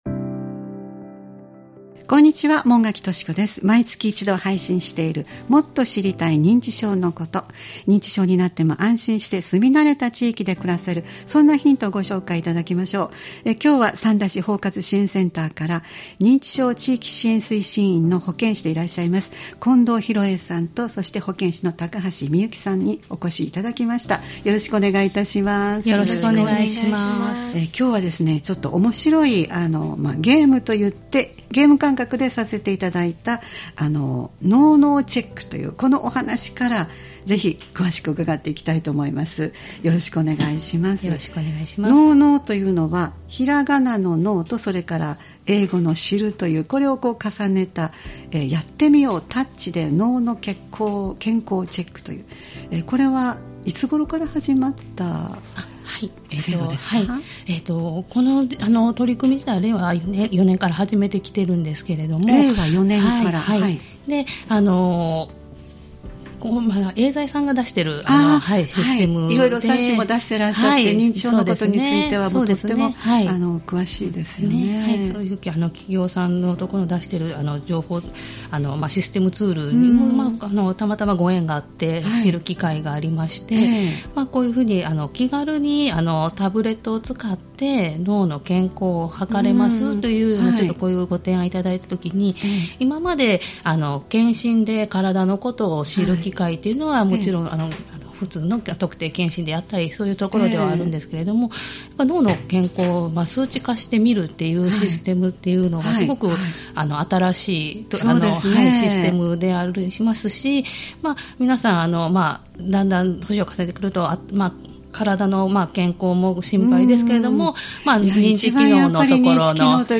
毎月第1月曜日に配信するポッドキャスト番組「もっと知りたい認知症のこと」 スタジオに専門の方をお迎えして、認知症に関連した情報、認知症予防の情報、介護や福祉サービスなどを紹介していただきます（再生ボタン▶を押すと番組が始まります）